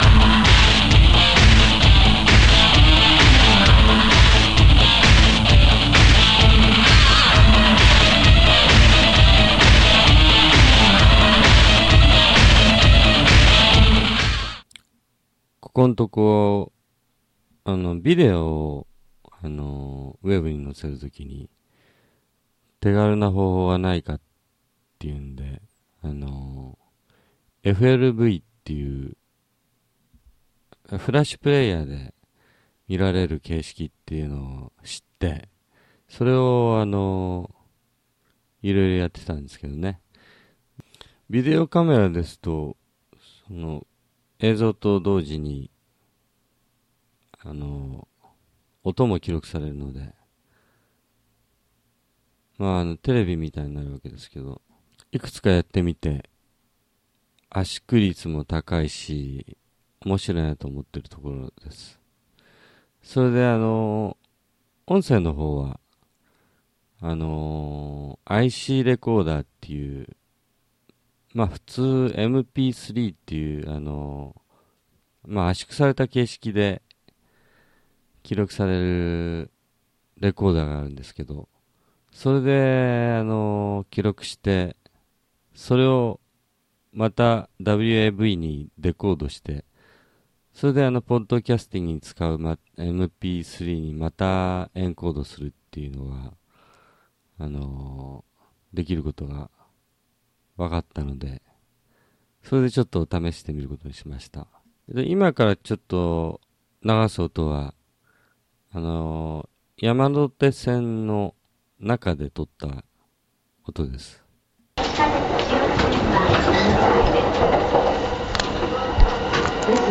初めての野外録音（詩の朗読Podcastingを始める前の雑談の録音テスト）